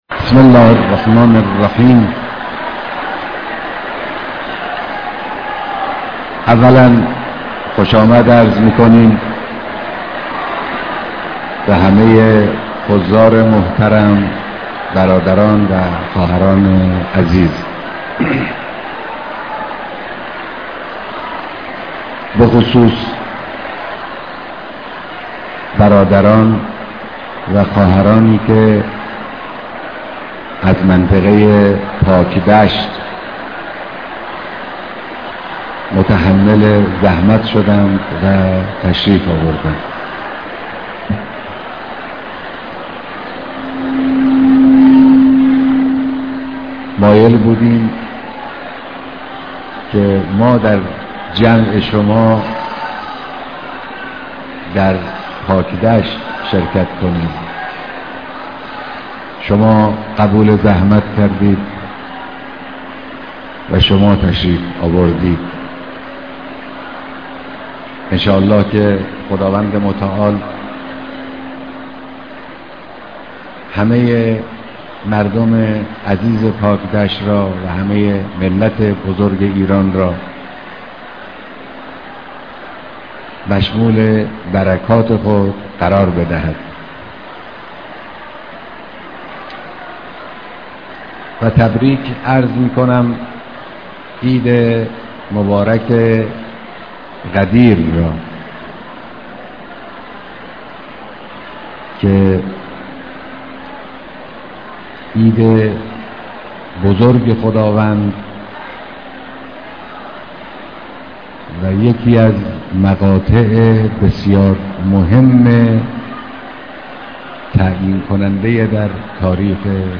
بيانات در ديدار مردم پاكدشت، در سالروز عيد سعيد غدير